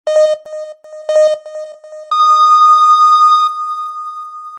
レースゲーム効果音。